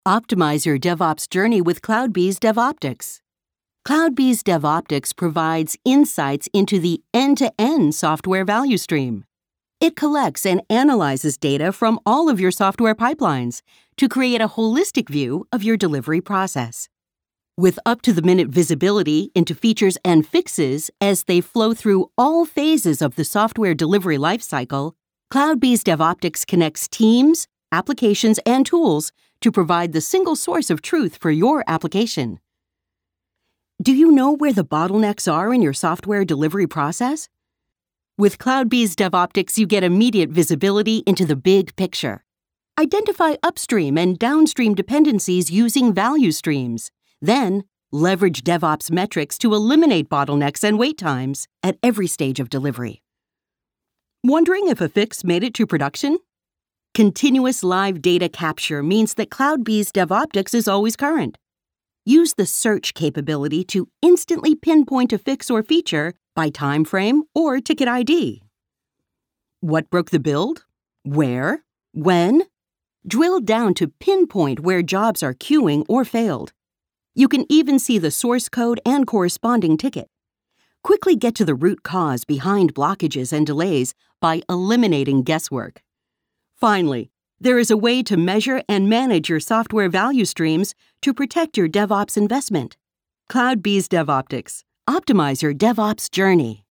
Female
English (North American)
Adult (30-50)
Corporate
Warm Presentation For Software